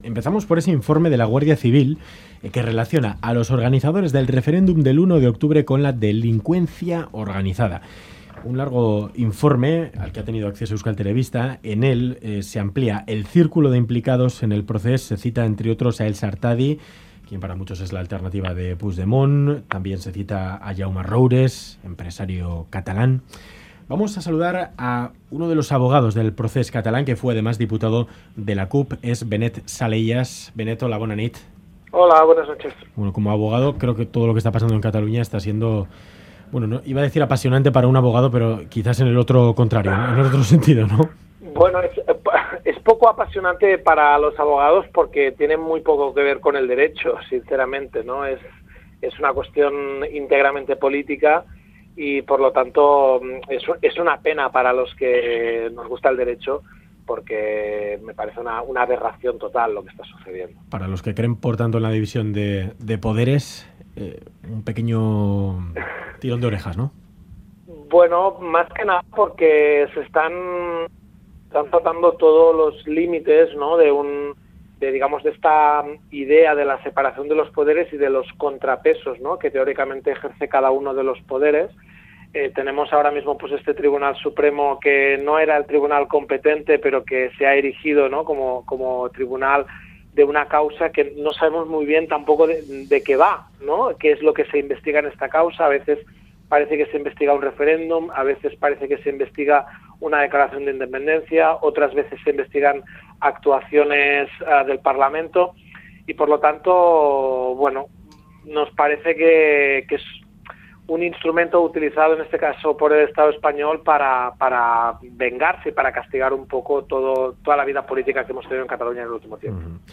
Audio: Entrevistado en 'Ganbara' de Radio Euskadi, uno de los abogados del proces catalá y ex diputado de la CUP, Benet Salellas, considera que 'tiene muy poco que ver con el derecho, es una cuestión íntegramente política'.
Radio Euskadi GANBARA 'Se están saltando todos los límites de la separación de poderes' Última actualización: 15/02/2018 22:39 (UTC+1) Entrevista a Benet Salellas, uno de los abogados del proces catalá y ex diputado de la CUP.